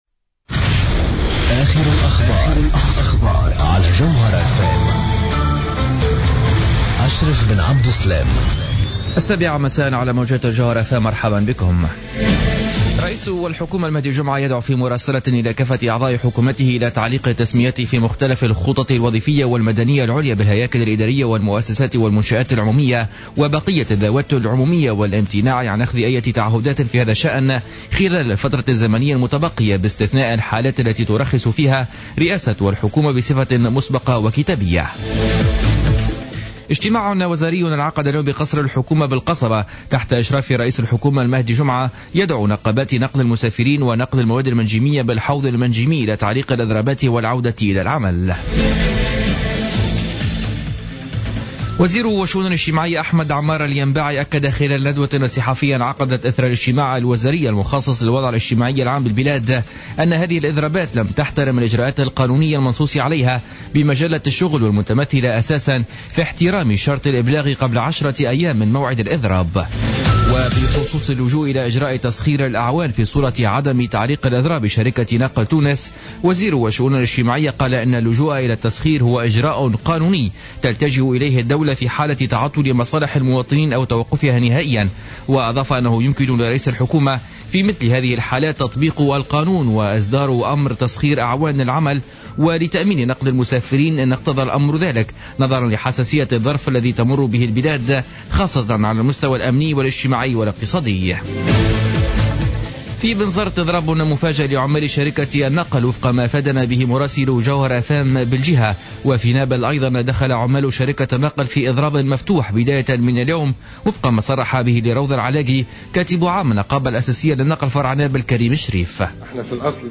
نشرة أخبار السابعة مساء ليوم الثلاثاء 13-01-15